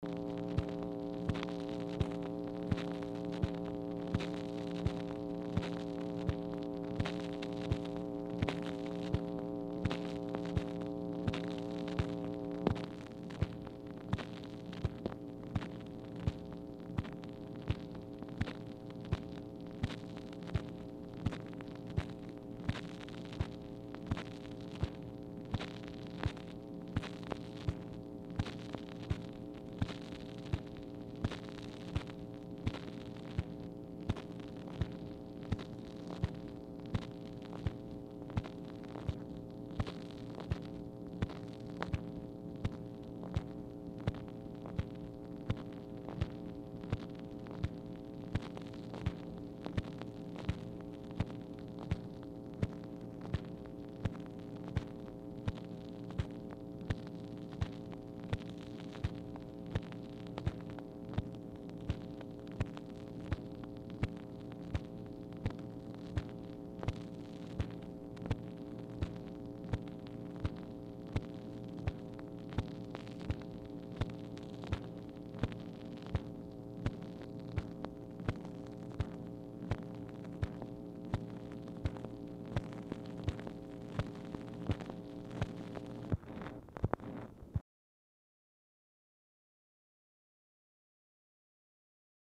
Telephone conversation # 5132, sound recording, MACHINE NOISE, 8/22/1964, time unknown | Discover LBJ
Format Dictation belt
Specific Item Type Telephone conversation